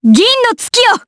Ripine-Vox_Skill6_jp.wav